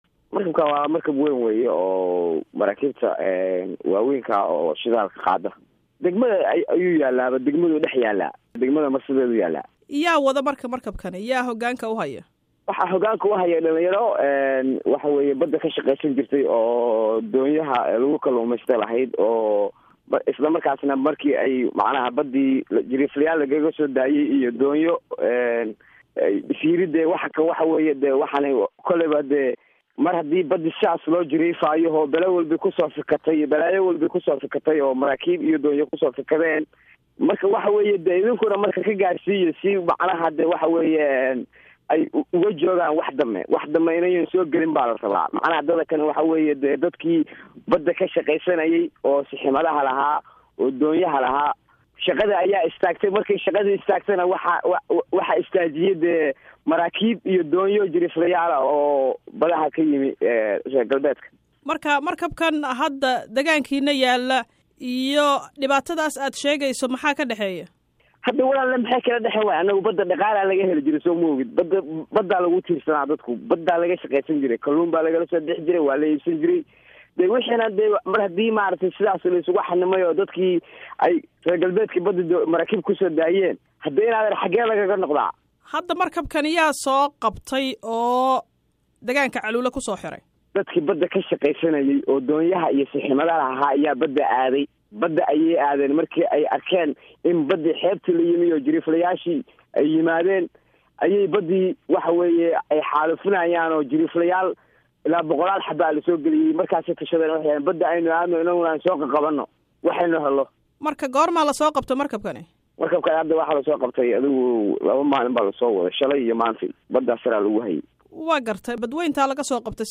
DHAGEYSO WAREYSIGA MARKABKA